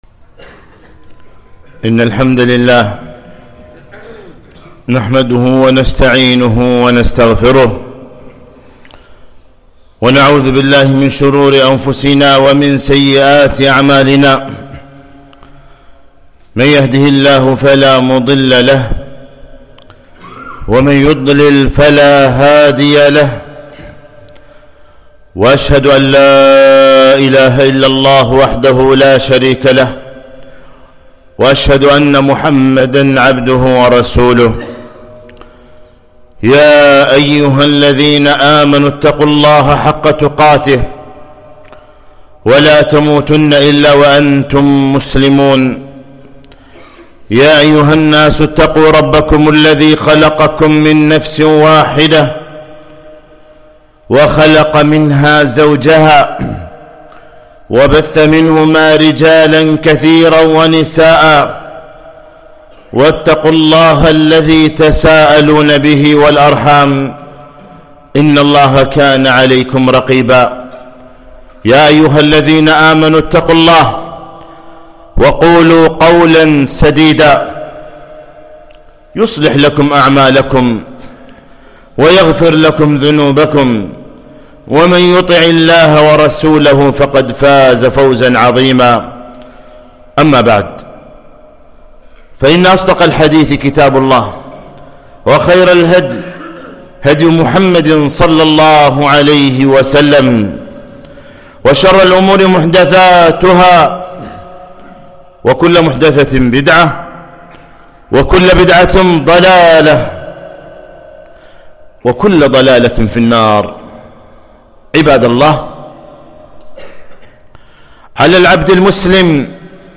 خطبة - ( قل هو الله أحد)